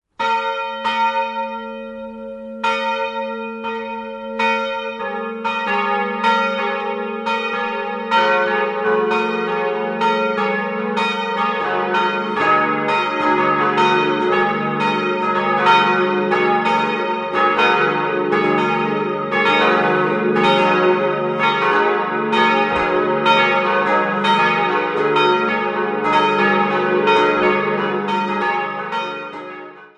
Die Martinskirche wurde in den 70er-Jahren nach Norden hin großzügig erweitert. Im Schnittpunkt von Alt- und Neubau befindet sich die Altarinsel. 4-stimmiges ausgefülltes Es-Moll-Geläute: es'-ges'-as'-b' Die Glocken wurden 1950 von Karl Czudnochowsky in Erding gegossen.